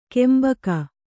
♪ kembaka'